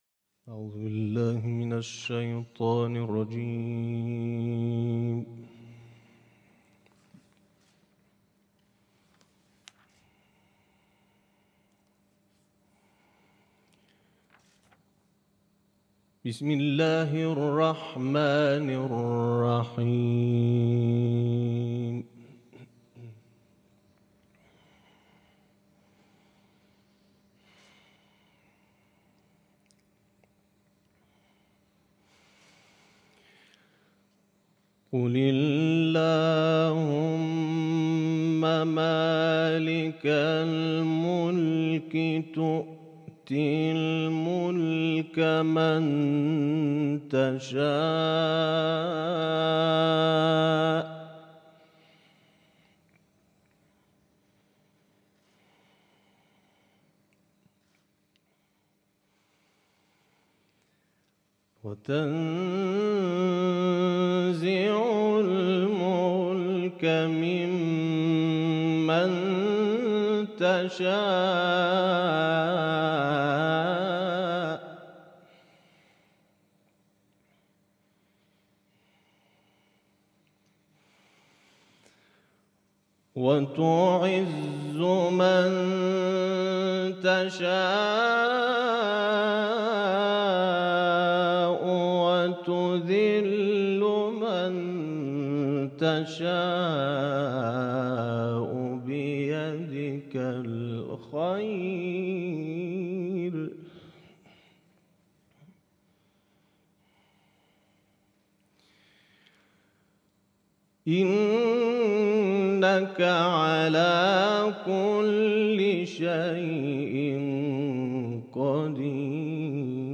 مراسم انس با قرآن در اولین روز از ماه مبارک رمضان